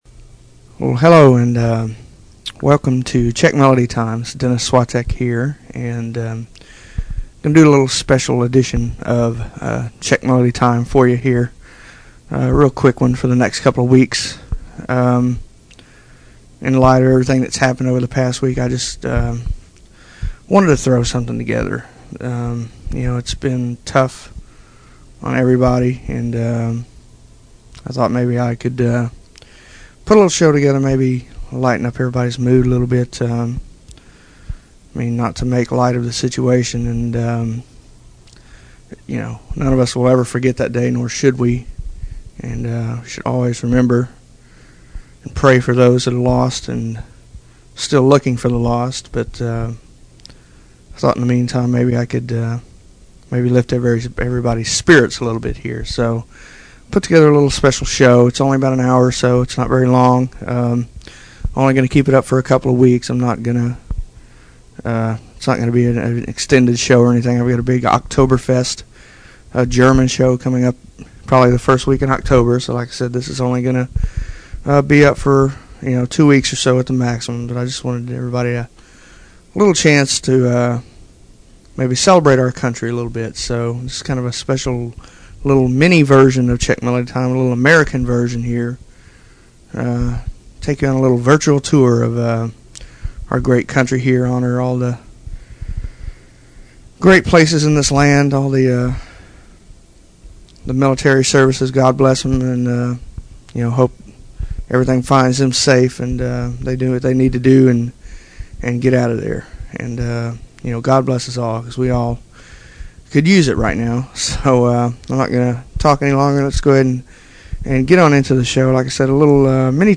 Polka
Waltz
March